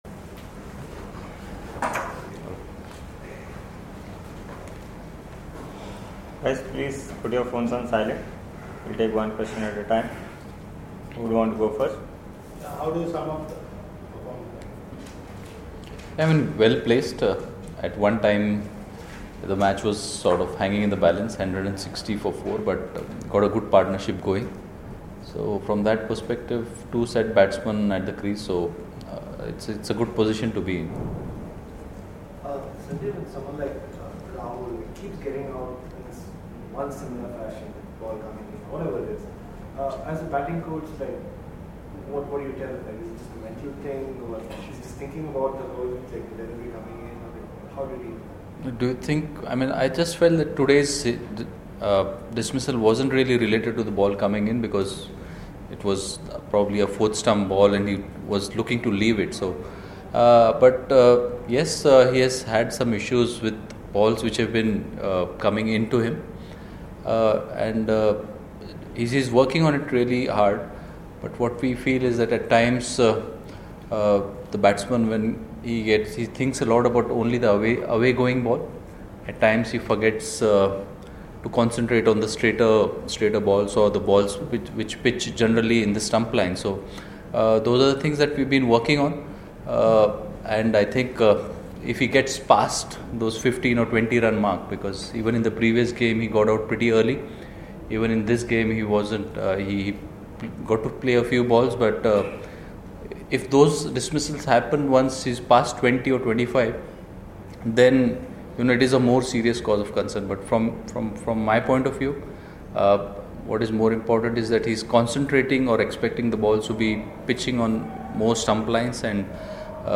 Sanjay Bangar, Assistant Coach, Indian Cricket Team speaks with the media after Day 2 of the 2nd Test against Windies
Speaks with the media at Hyderabad on Saturday, October 13, after Day 2 of the 2nd Test against Windies.